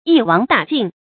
注音：ㄧ ㄨㄤˇ ㄉㄚˇ ㄐㄧㄣˋ
一網打盡的讀法